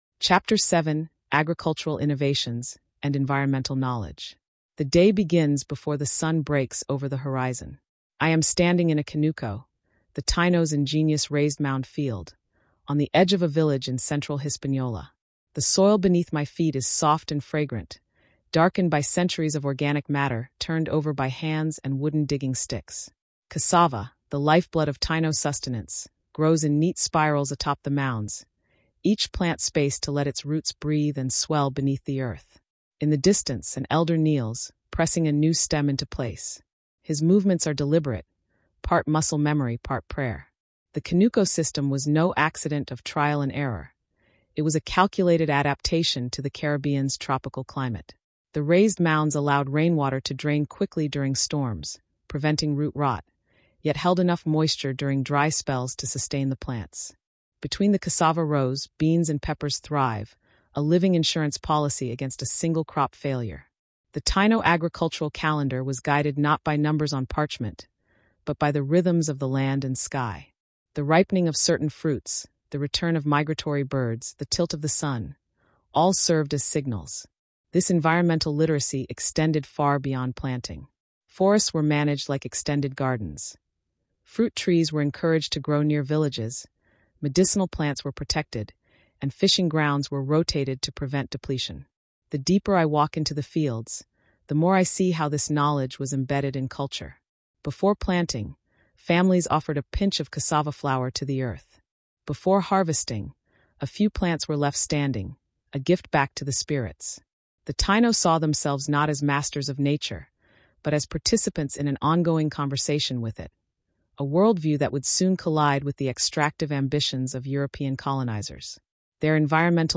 Learn how they fed their communities, forged alliances across the sea, and honored the spirits who shaped their destiny. Told in a vivid Smithsonian-style narrative, this is a journey into the living heart of the Caribbean’s first great civilization.